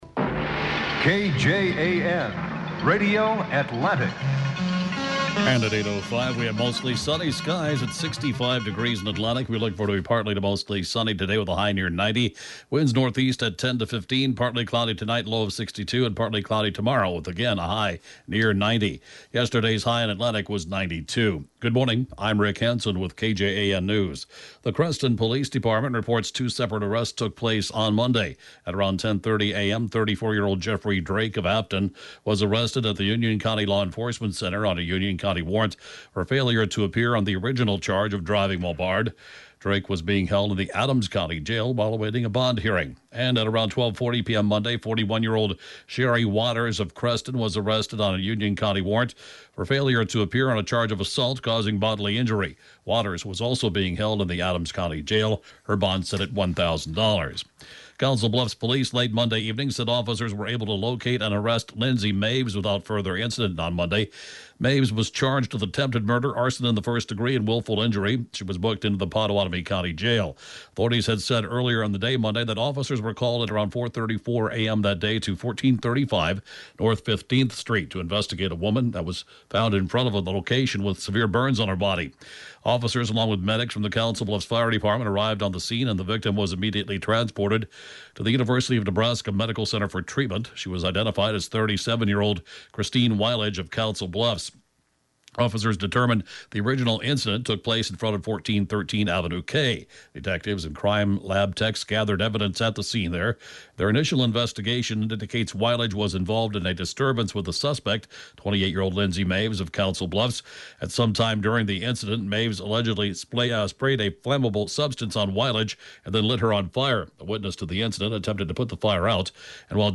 (Podcast) KJAN 8:05-a.m. News, 6/15/21